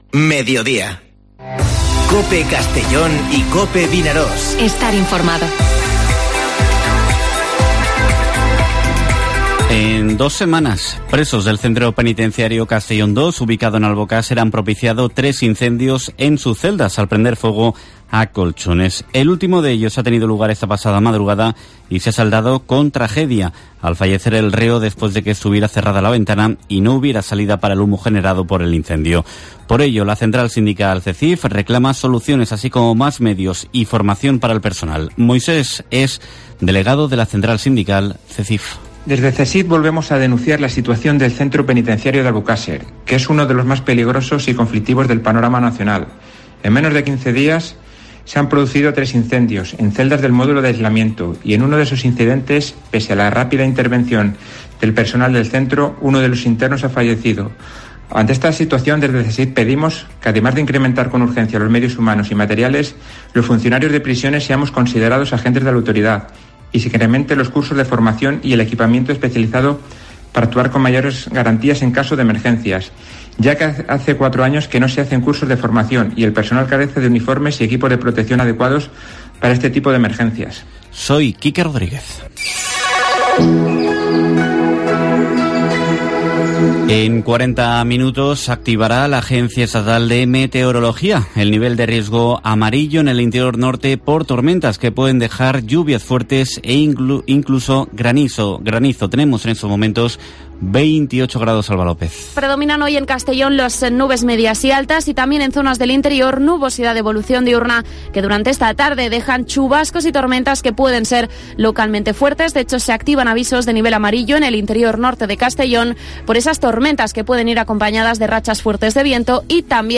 Informativo Mediodía COPE en la provincia de Castellón (23/08/2021)